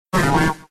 Cri de Chétiflor K.O. dans Pokémon X et Y.